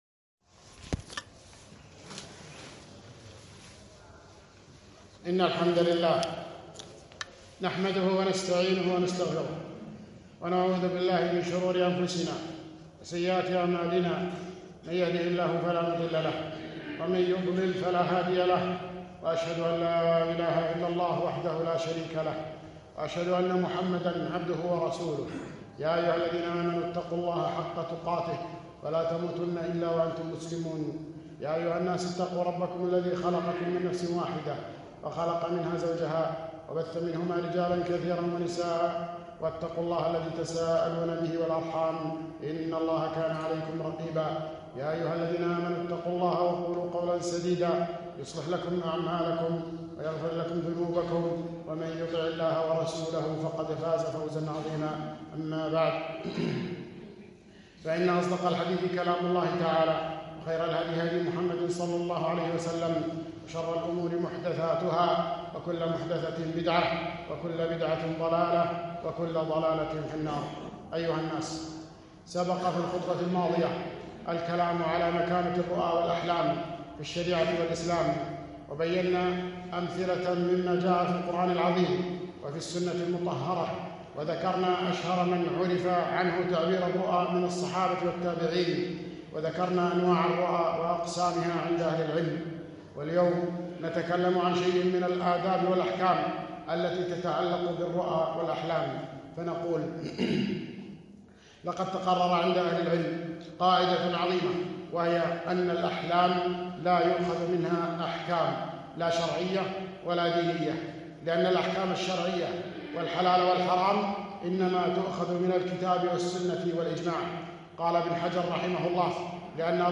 خطبة - الرؤى والأحلام ٢